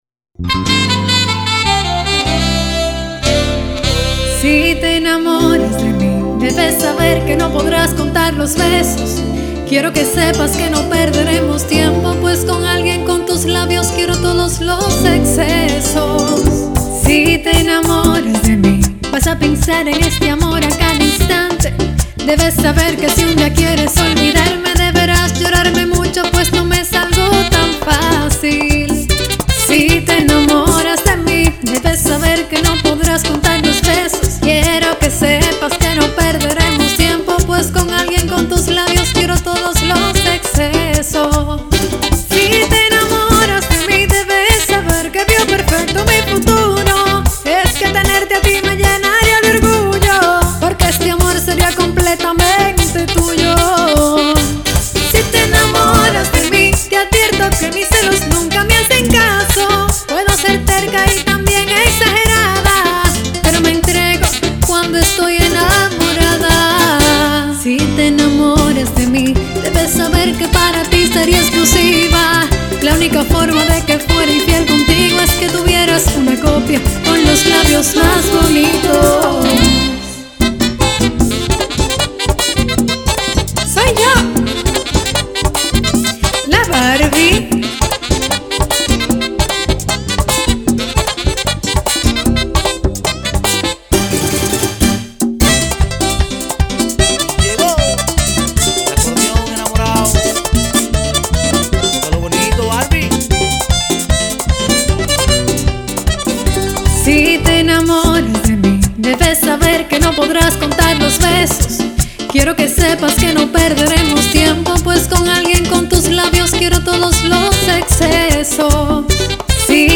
en la música popular o merengue típico.